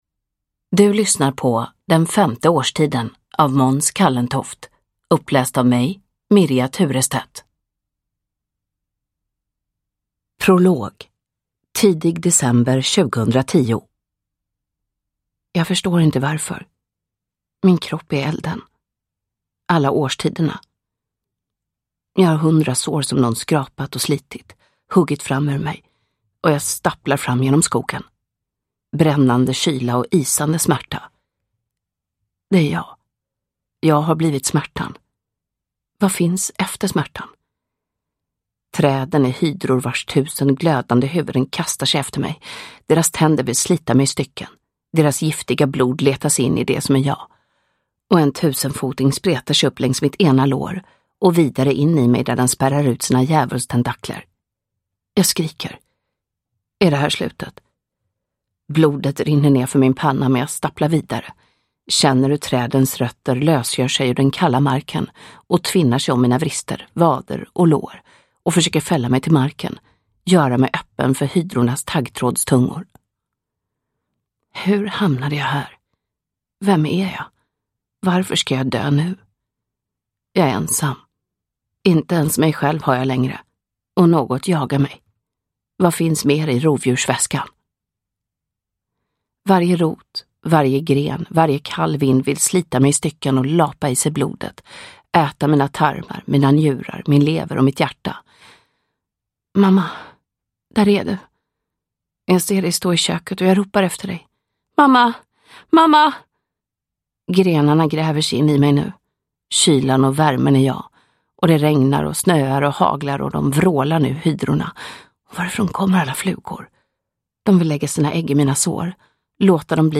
Den femte årstiden – Ljudbok – Laddas ner
Uppläsare: Mirja Turestedt